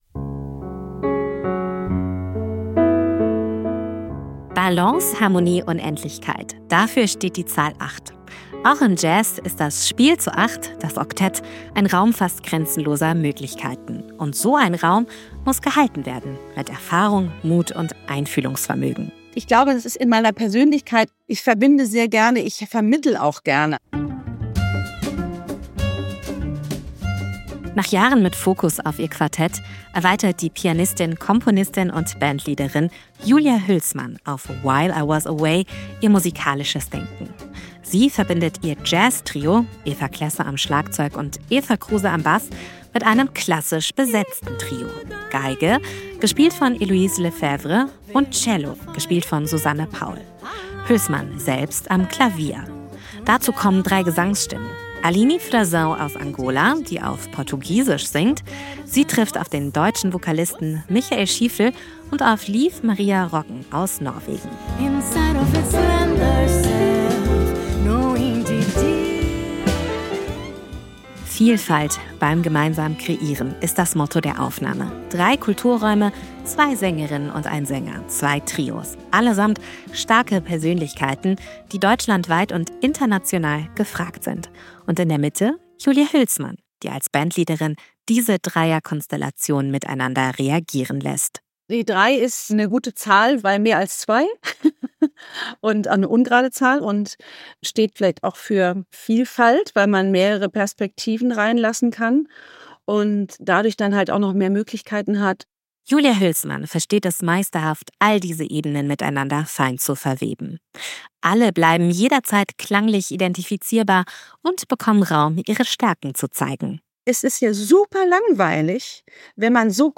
Album-Tipp Jazz
Sie verbindet ihr Jazztrio mit einem klassisch besetzten Klaviertrio und Gesangsstimmen.